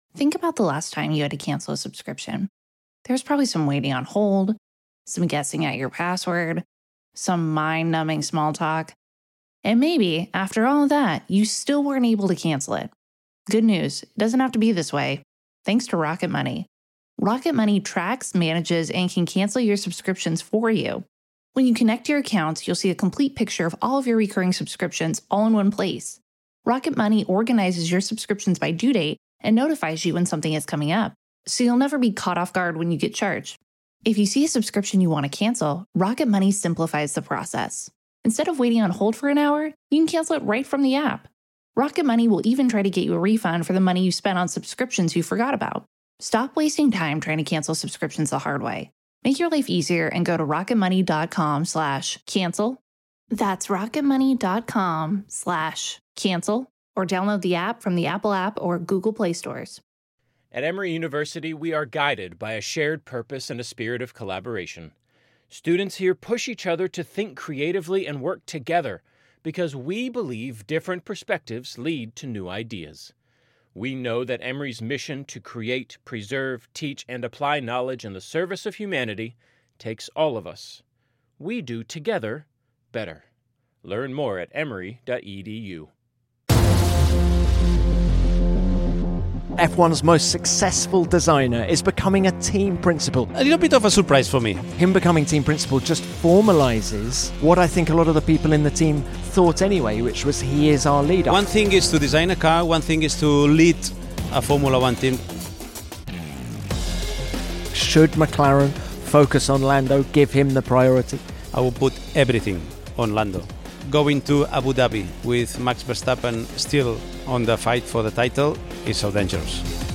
Spanish journalist
in the Qatar paddock to discuss the news that Adrian Newey will become Aston Martin Team Principal from 2026.